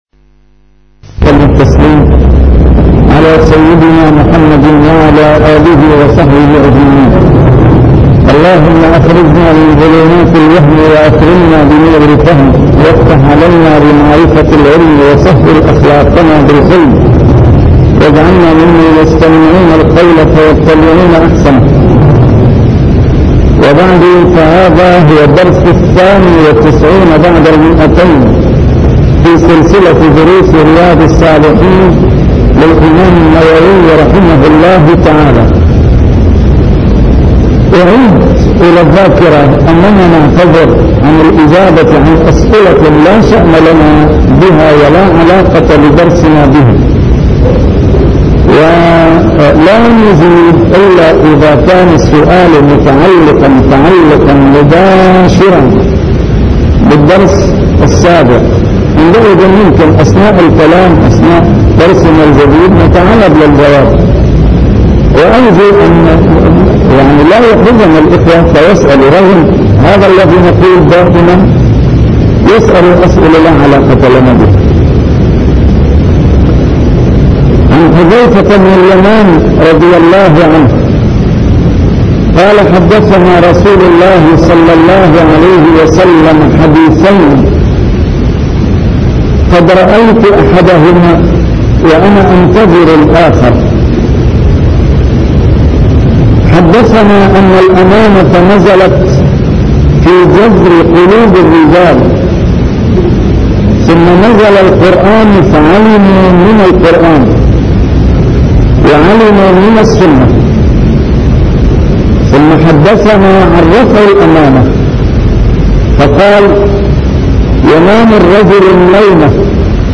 A MARTYR SCHOLAR: IMAM MUHAMMAD SAEED RAMADAN AL-BOUTI - الدروس العلمية - شرح كتاب رياض الصالحين - 292- شرح رياض الصالحين: الأمر بأداء الأمانة